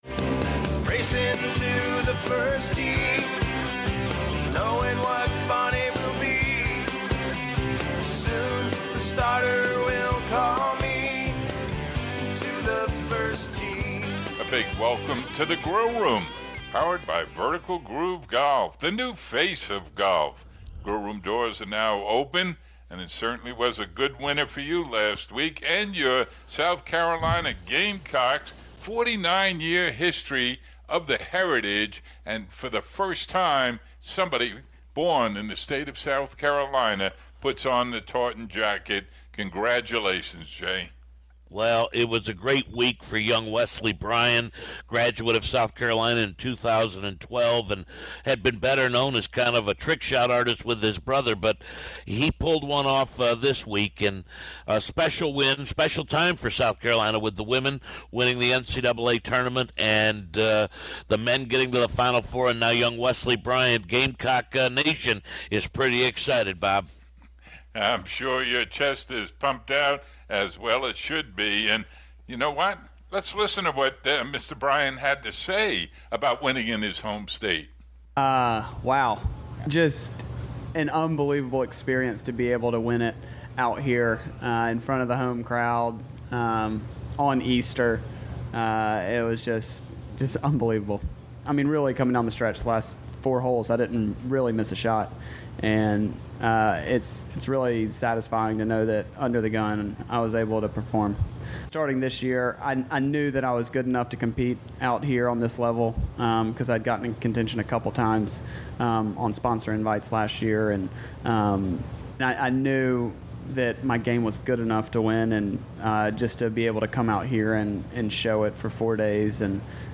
In-depth preview of the PGA TOUR Champions Bass Pro Shops Legends of Golf at Big Cedar Lodge where Tiger announced his newest course and the Valero Texas Open. Interview with Paul McGinley, former European Ryder Cup Captain.